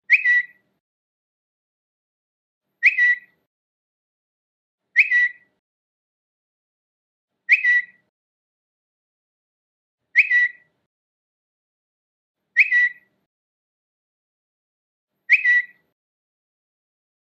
เสียงแจ้งเตือน Twitter อย่างต่อเนื่อง
หมวดหมู่: เสียงเรียกเข้า
am-thanh-thong-bao-twitter-lien-tuc-th-www_tiengdong_com.mp3